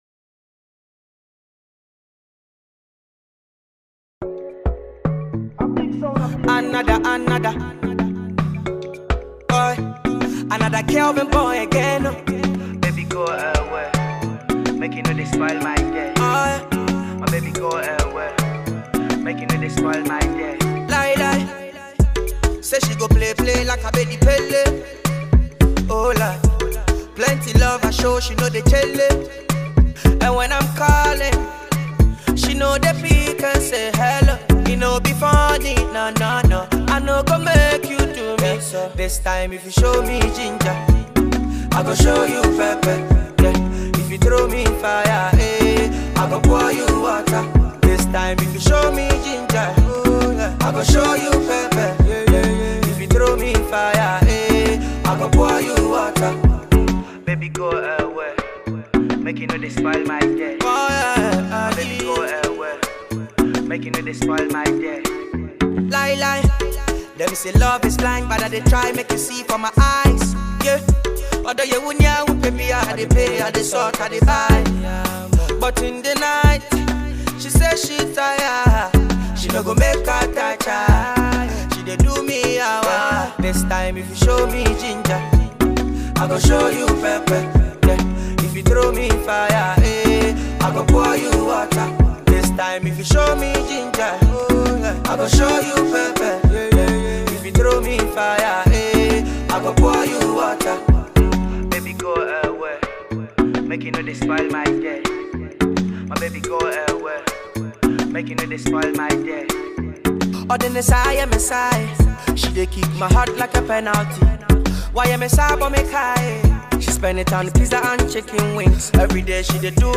soothing Afrobeat song